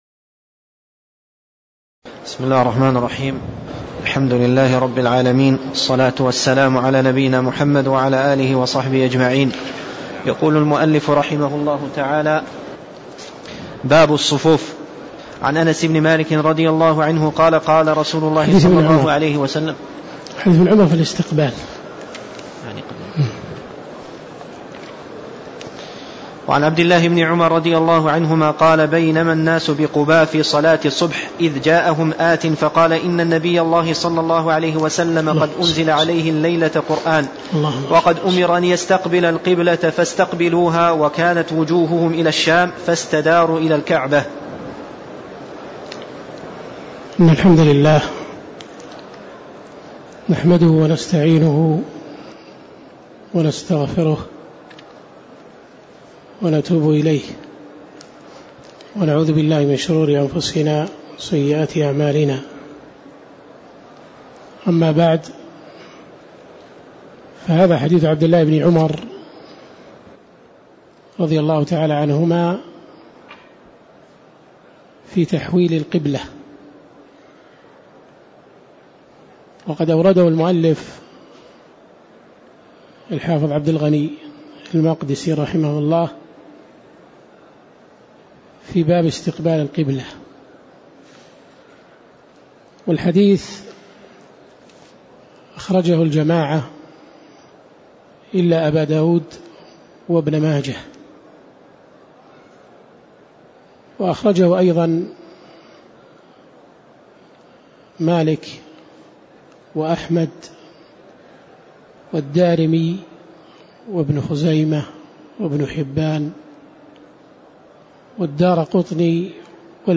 تاريخ النشر ٨ رجب ١٤٣٦ هـ المكان: المسجد النبوي الشيخ